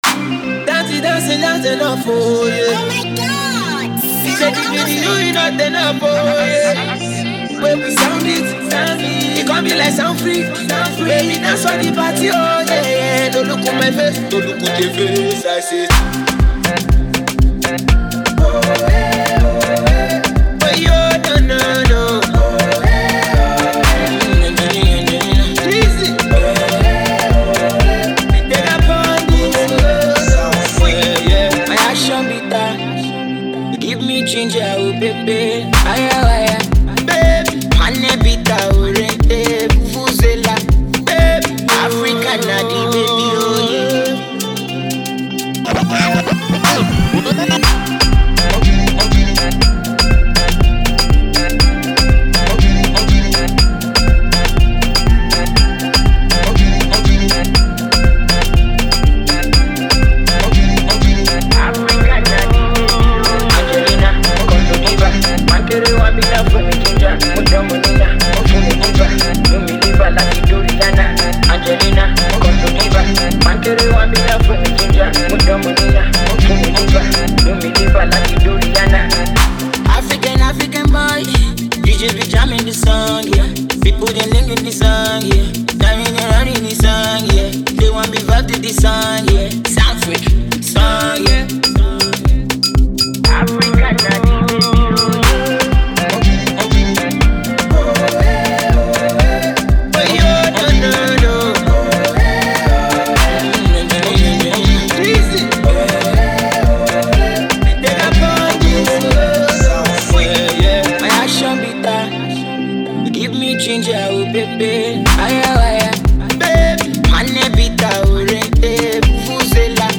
the party riddim